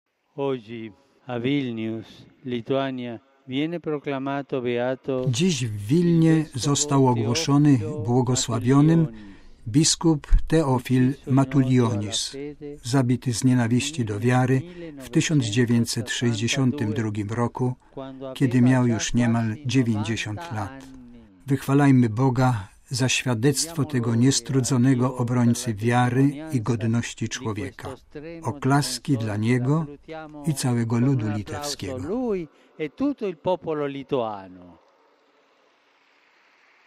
Po niedzielnej modlitwie Anioł Pański Papież w kilku słowach wspomniał też o beatyfikacji litewskiego męczennika.